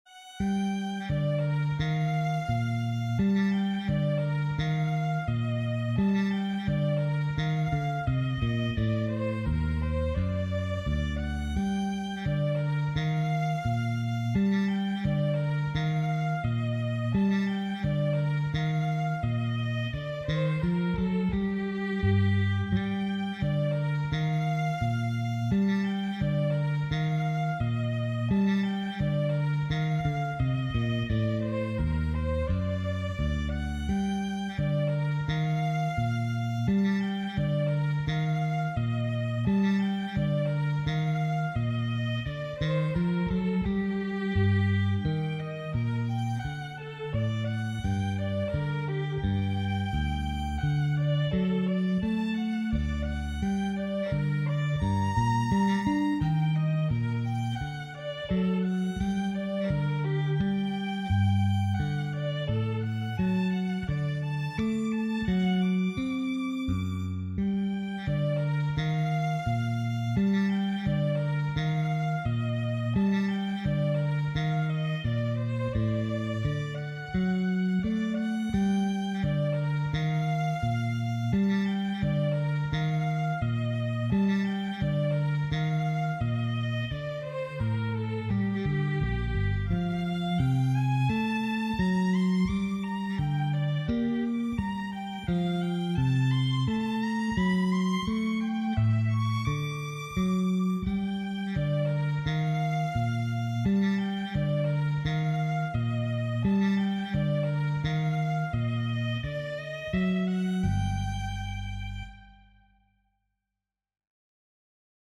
This represents literally decades of guitar foolery.